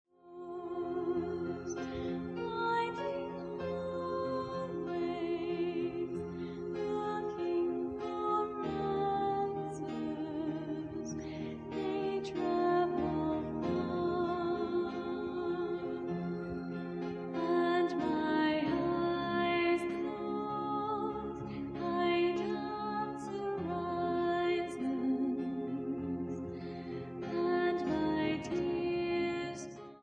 Her style ranges between New Age and Electronic.
This CD is a live recording.